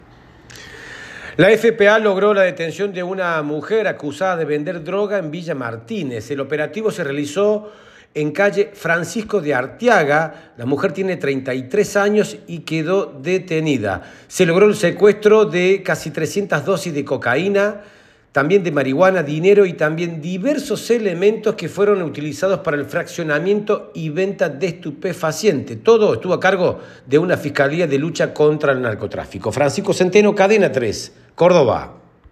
Detienen a una mujer por vender drogas en Villa Martínez - Boletín informativo - Cadena 3 - Cadena 3 Argentina